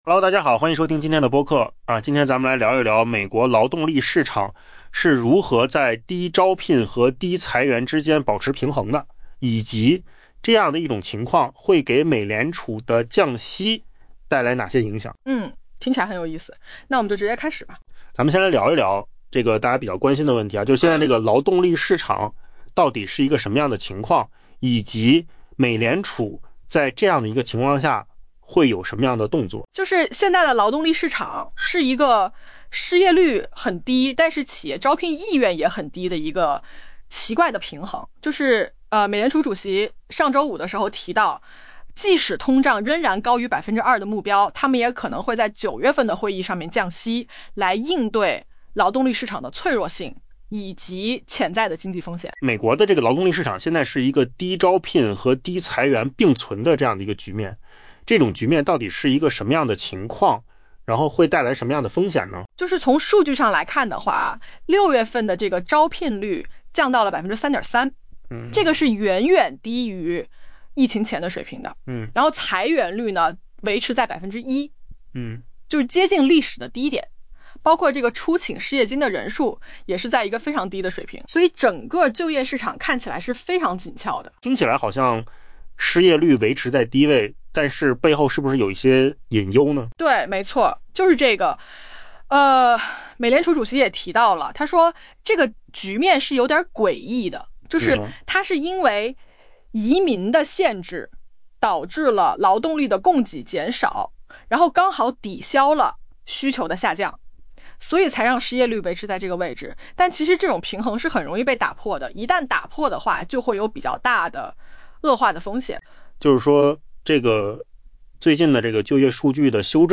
AI播客：换个方式听细纹 下载mp3
音频由扣子空间生成